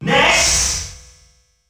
The announcer saying Ness' name in French releases of Super Smash Bros.
Ness_French_Announcer_SSB.wav